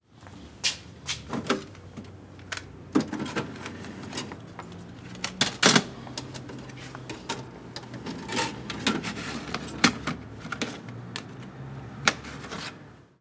Poner disco de vinilo en tocadisco
Reproductor de discos (Pick-up)
Sonidos: Especiales
Sonidos: Hogar